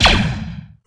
b2_blaster.wav